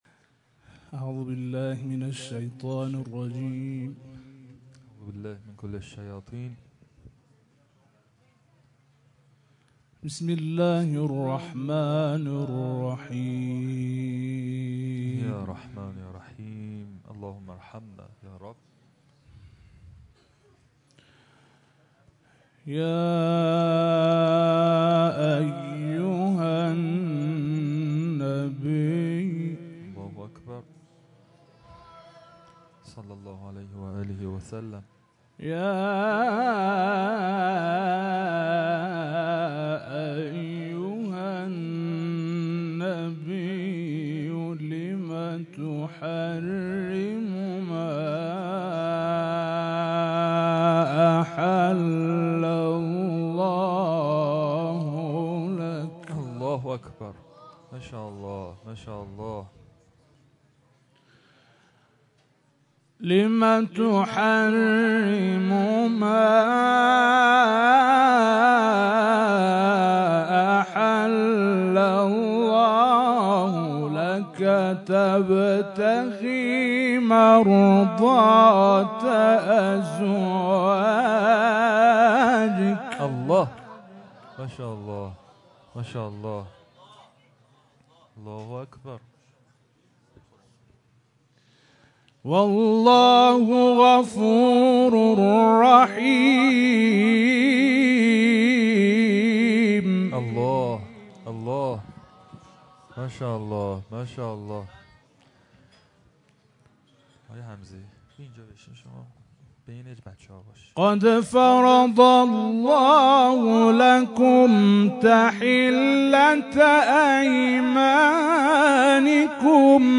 IQNA - Qari internasional negara Iran melantunkan ayat-ayat suci Alquran saat hadir di Husainiyah Fatimah az-Zahra (as) pada hari raya Ghadir Khum.